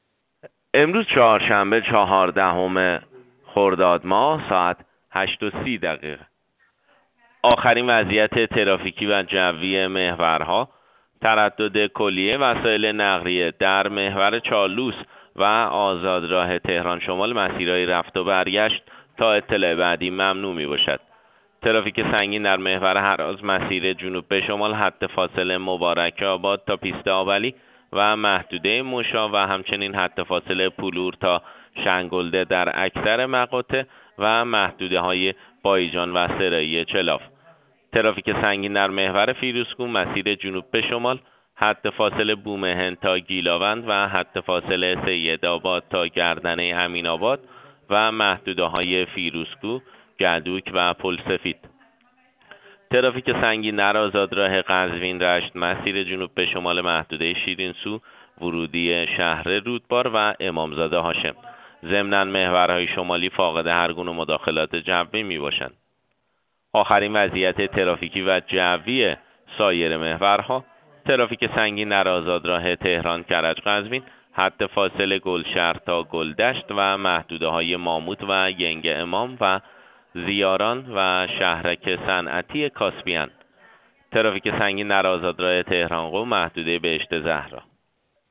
گزارش رادیو اینترنتی پایگاه‌ خبری از آخرین وضعیت آب‌وهوای۱۴ خرداد؛